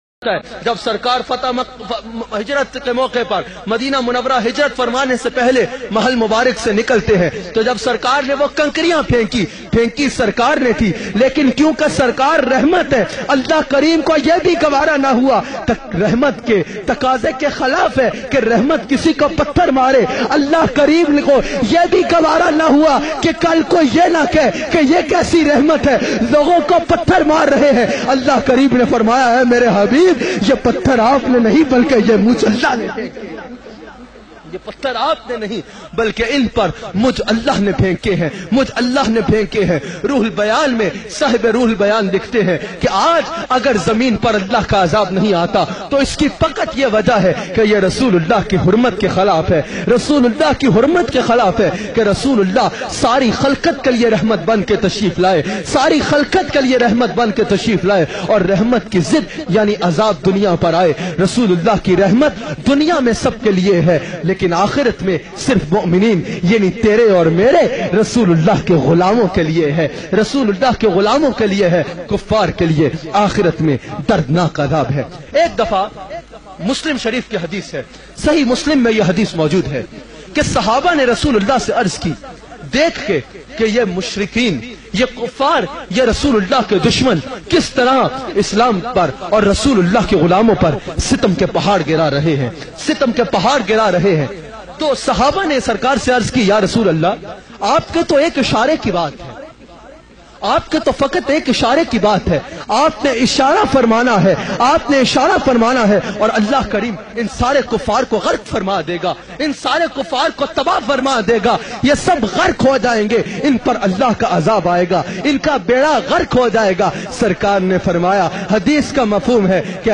Did Islam spread by force bayan mp3